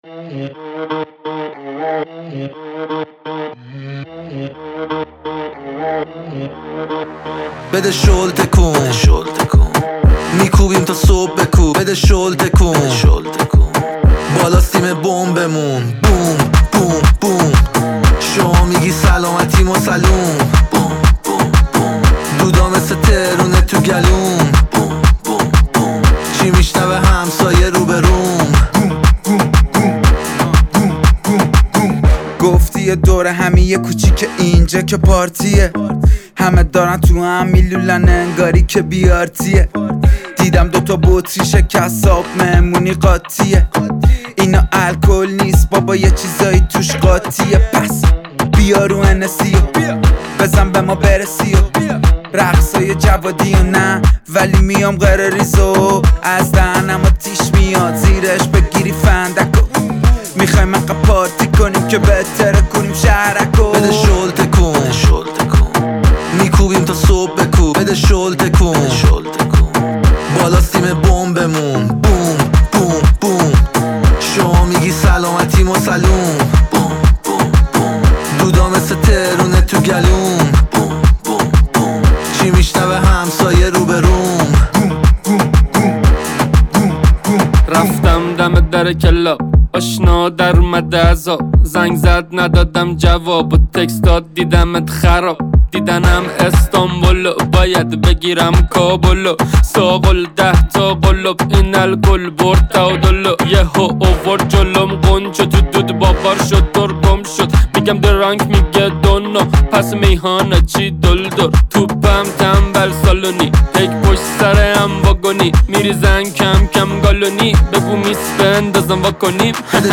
هیپ هاپ